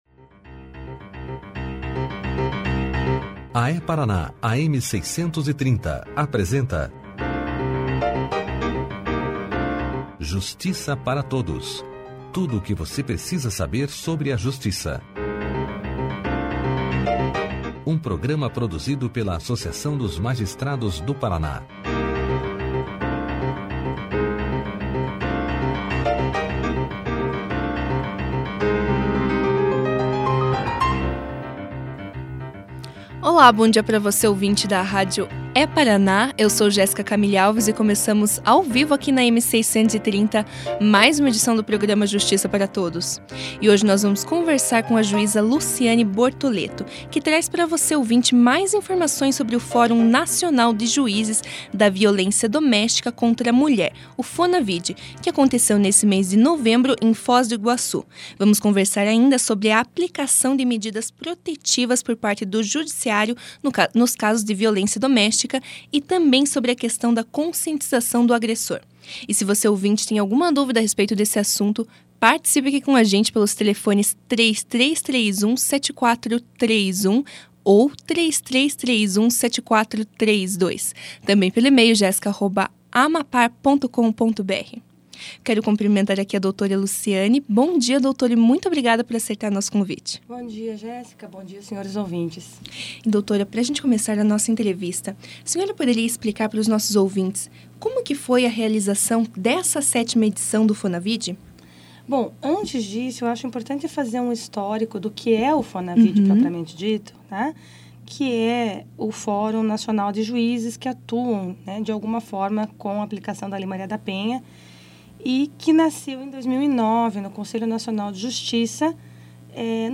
No programa Justiça Para Todos dessa segunda-feira (07), a juíza Luciane Botoleto levou aos ouvintes da rádio É-Paraná mais informações sobre o Fórum Nacional de Juízes da Violência Doméstica contra a mulher (FONAVID), que aconteceu em novembro de 2015, em Foz do Iguaçu. A magistrada ainda falou sobre a aplicação de medidas protetivas por parte do judiciário nos casos de violência doméstica e também sobre a questão da conscientização do agressor.
Clique aqui e ouça a entrevista da juíza Luciane Bortoleto sobre o Fórum Nacional de Juízes da Violência Doméstica contra a mulher (FONAVID) na íntegra.